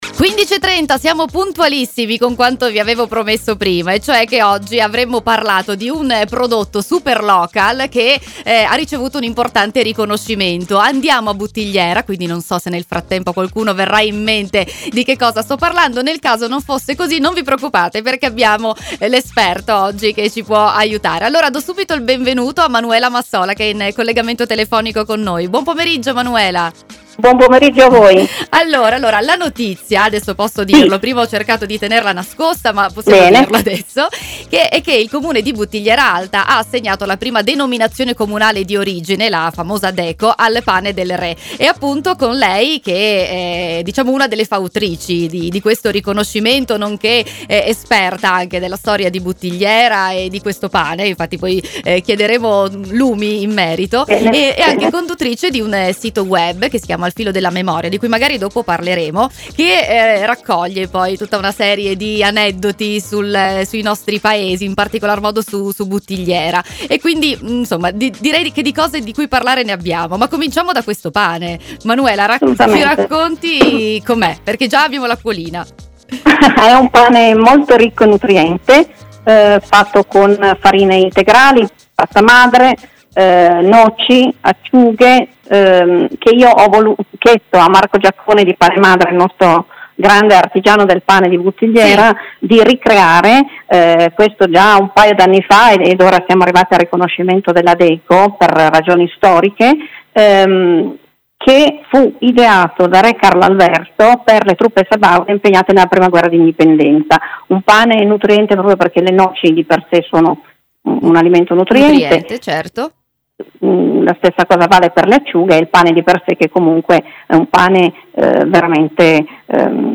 Ne abbiamo parlato proprio con lei nella nostra intervista che potrete riascoltare qui.